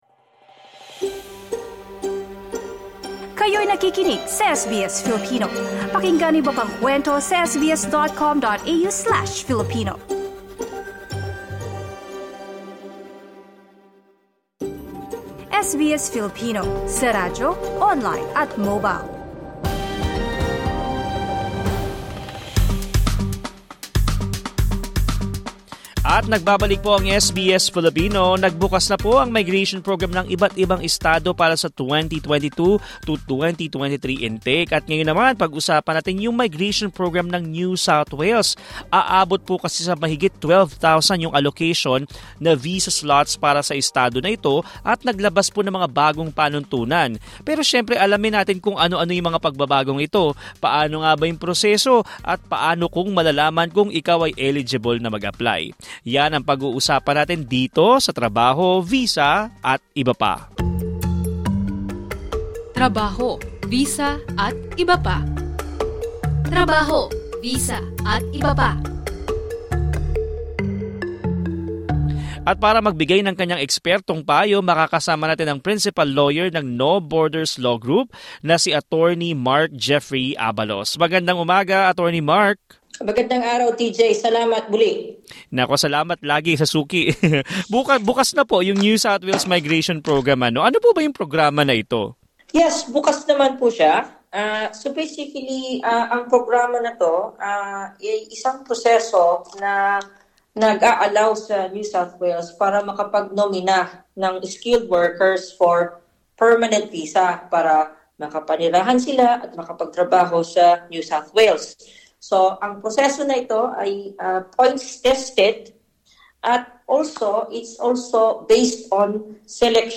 In this episode of ‘Trabaho, Visa, atbp.’ an immigration lawyer discussed the new rules and occupations added to the NSW Migration Program 2022-2023.